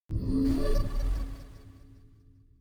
portal_enter_002.wav